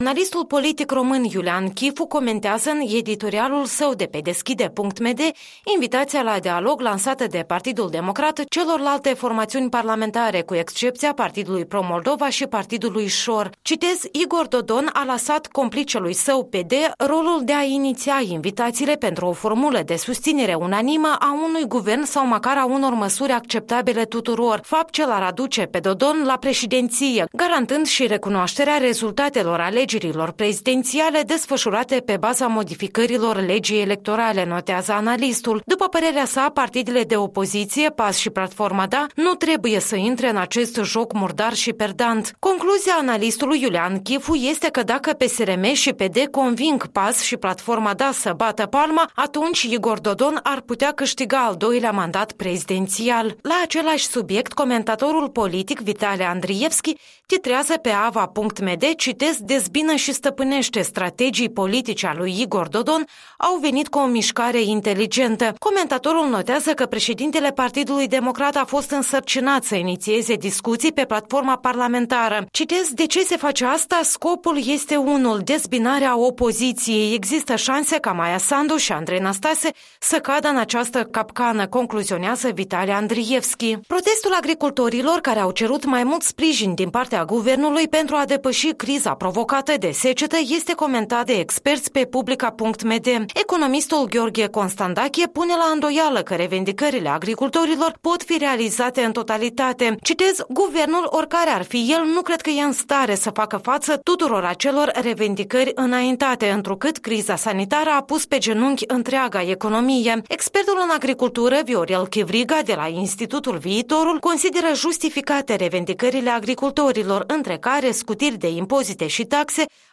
Revista matinală a presei.